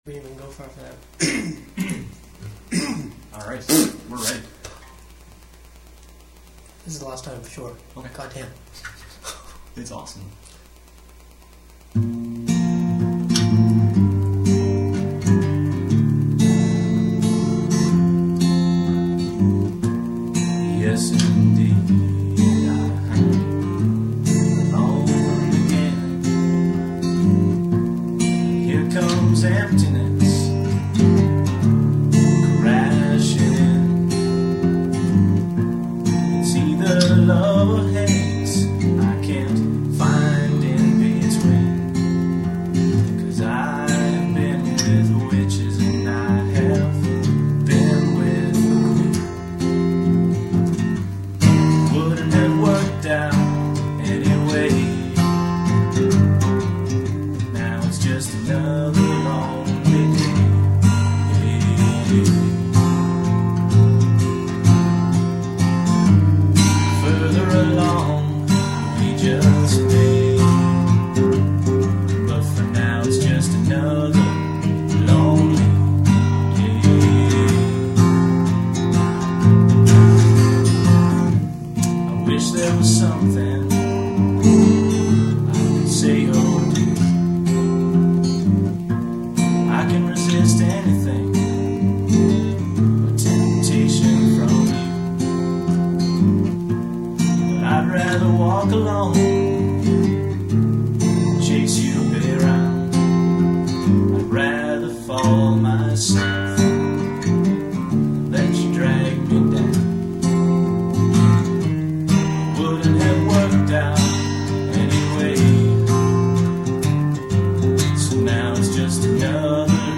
You’ll notice we did a few takes that night.